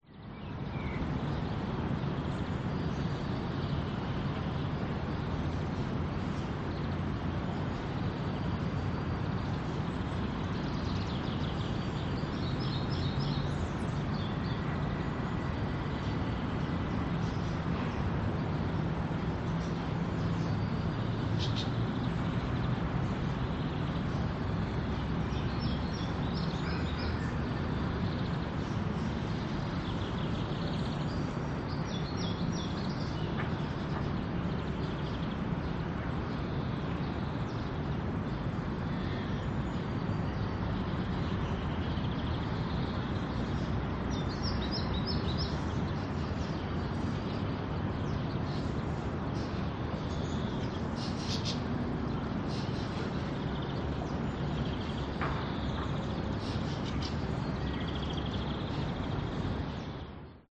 Звуки атмосферы
Окружающая среда и парк удаленный трафик среднее напряжение